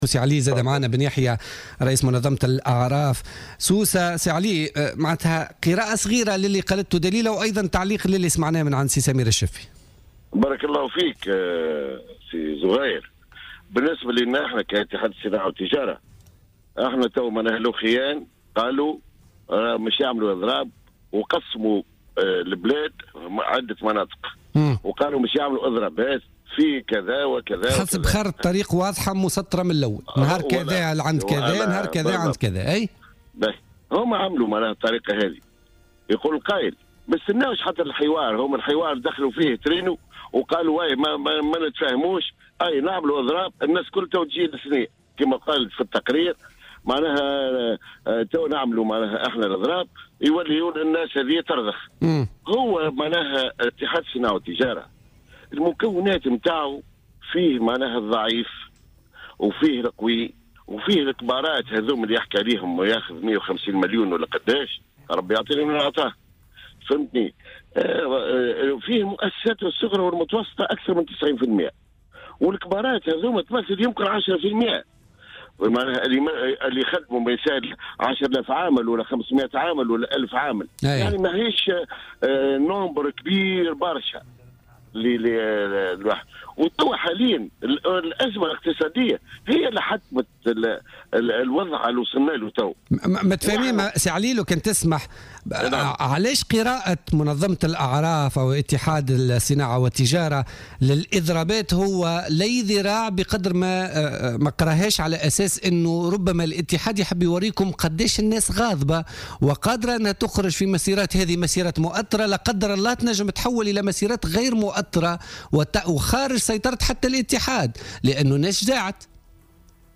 تلاسن